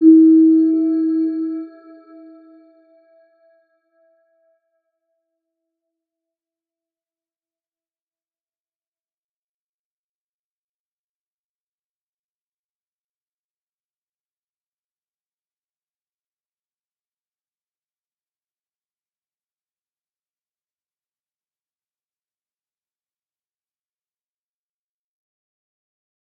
Round-Bell-E4-mf.wav